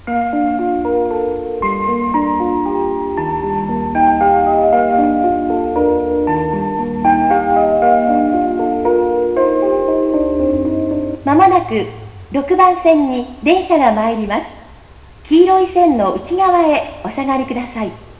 これでも京王線ではなく南浦和だという。…
minamiurawa6_ap.wav